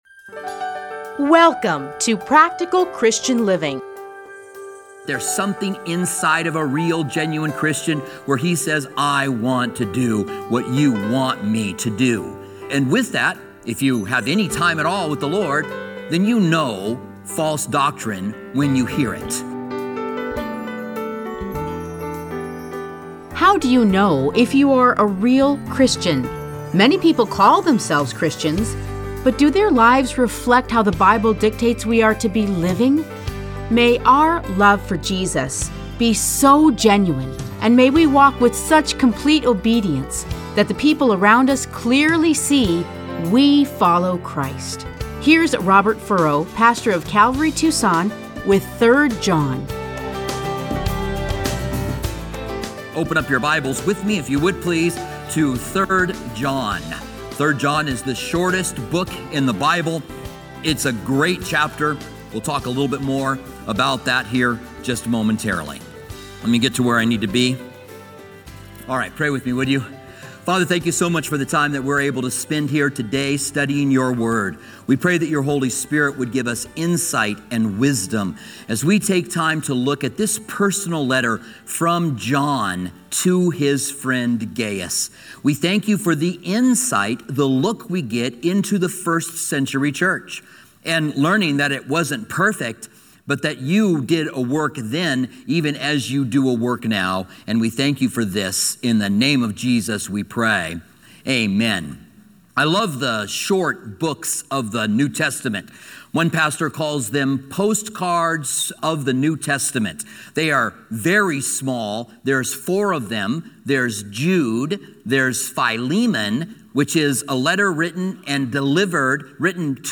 Listen to a teaching from 3 John 1-15.